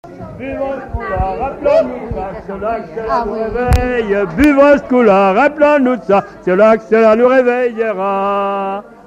circonstance : bachique
Genre brève
Pièce musicale inédite